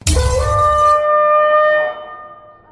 round_lose.mp3